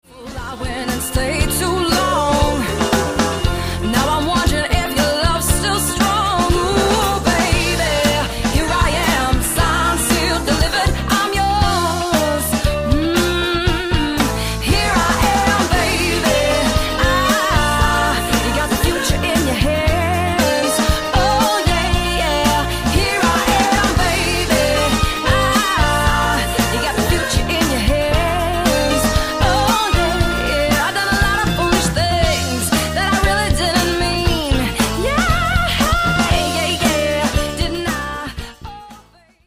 Corporate and Wedding Cover Band Hire Melbourne